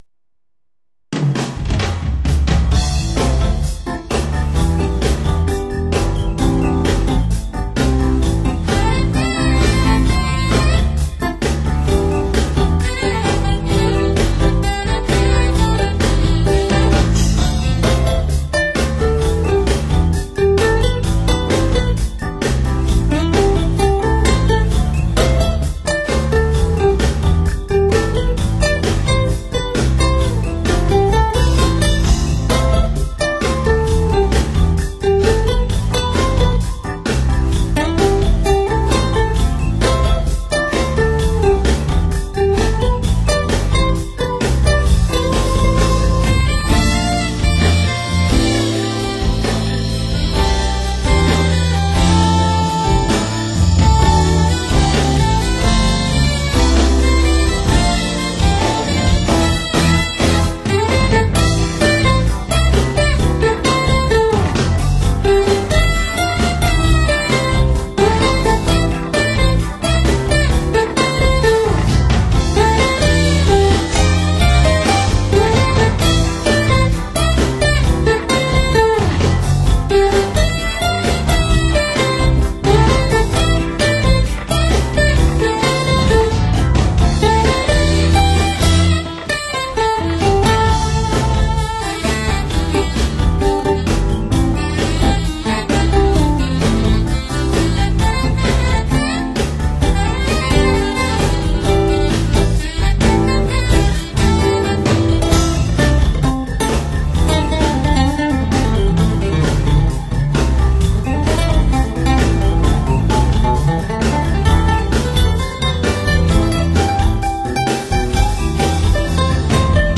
라이브